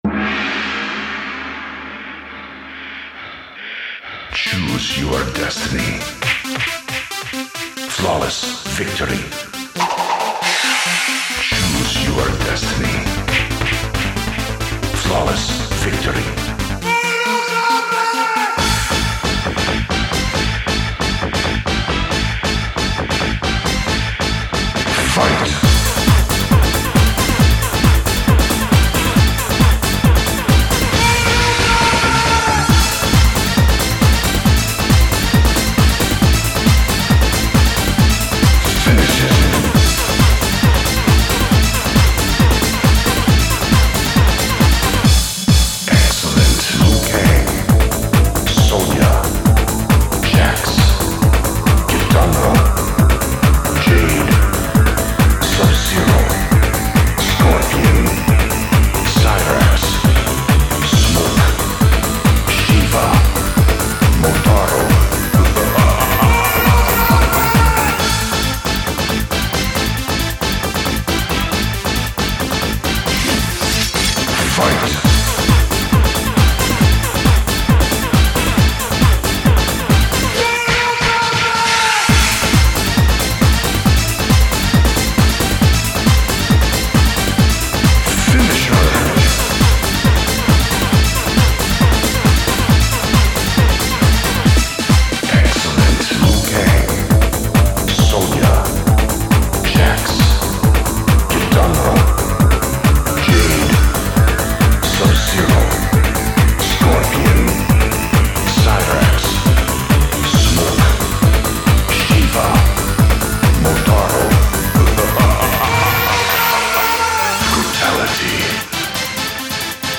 Tags: alternative rock random sounds funny sounds